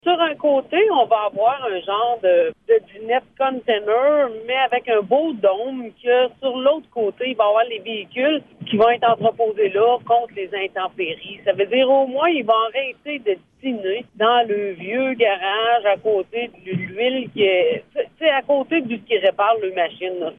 Qu’à cela ne tienne, les membres du conseil ont décidé d’avancer dans le dossier en procédant à l’achat d’un conteneur et d’un dôme pour le service des travaux publics. La mairesse de Déléage, Anne Potvin, explique ce qui sera fait :